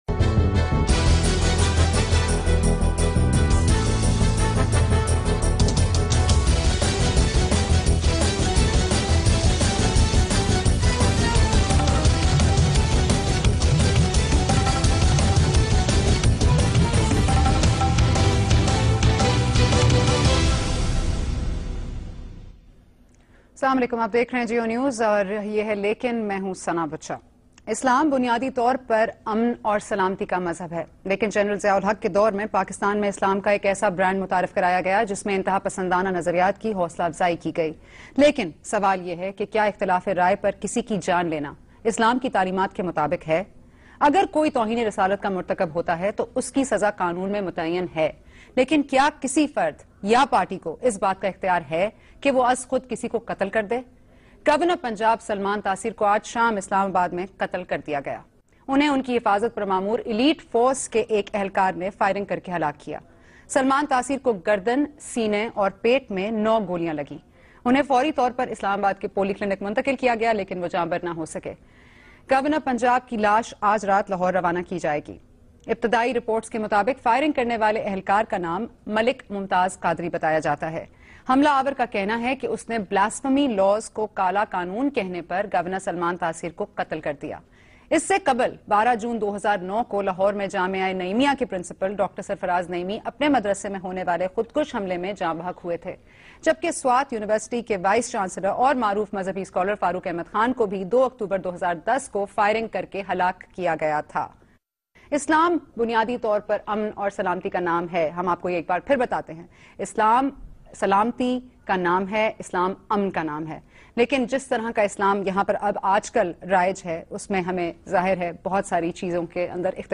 Category: TV Programs / Geo Tv /